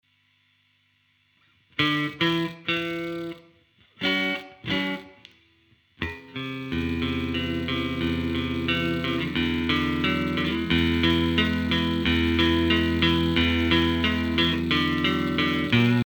Digidesign 003 console треск на входах,ЧТо делать
треск пропадает. я скинул семпл что бы понять какого типа этот треск.